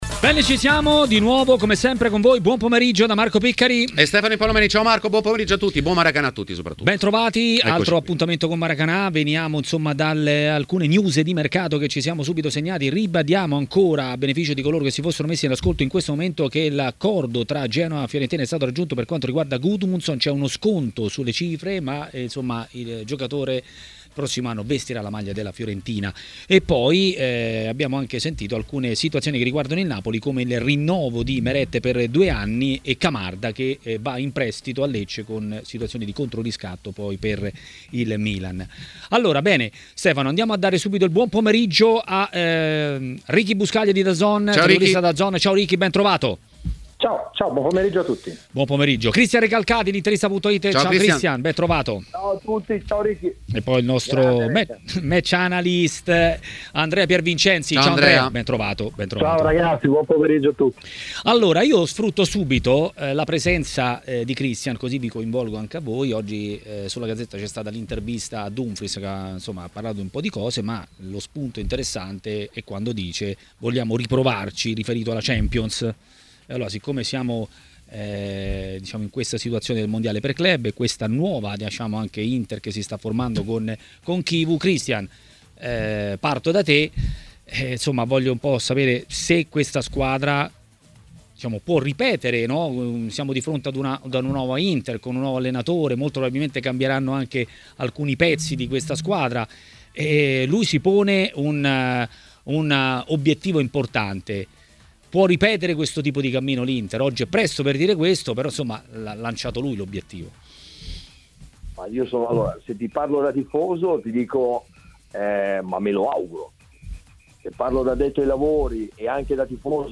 A Maracanà, nel pomeriggio di TMW Radio
giornalista ed ex calciatore.